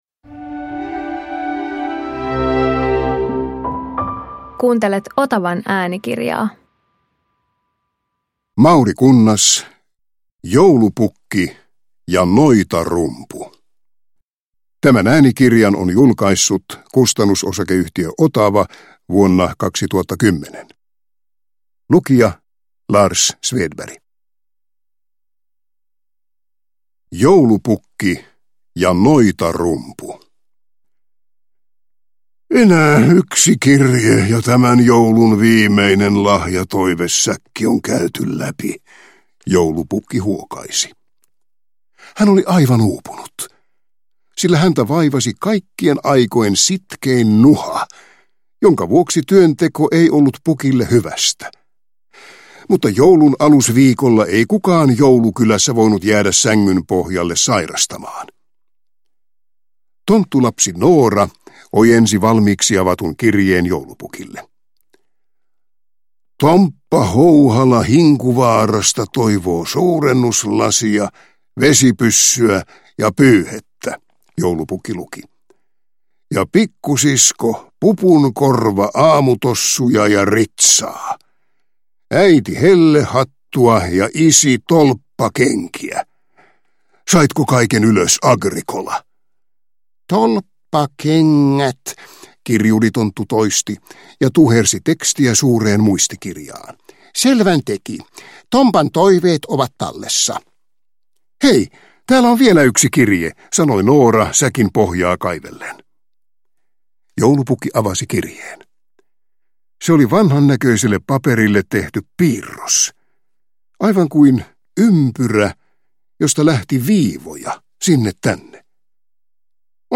Joulupukki ja noitarumpu – Ljudbok